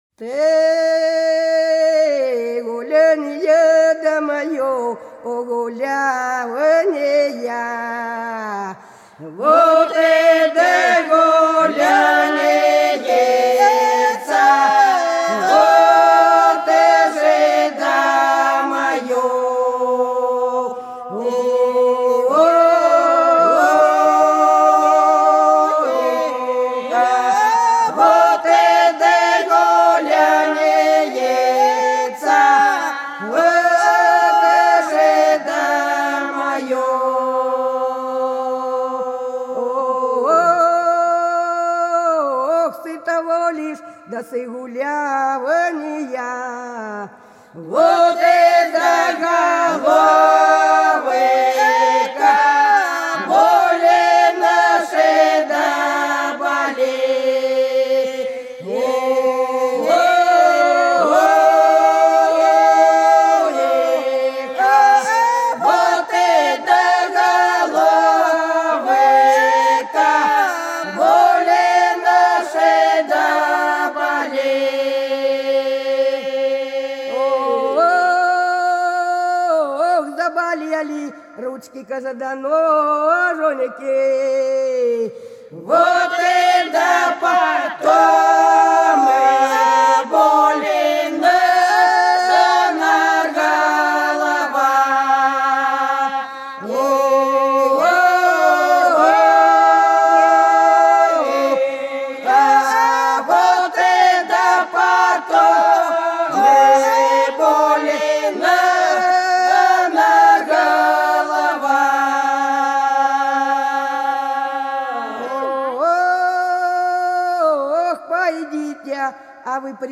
Хороша наша деревня Ты гулянье, да моё гулянье - протяжная (с. Иловка)
02_Ты_гулянье,_да_моё_гулянье_-_протяжная.mp3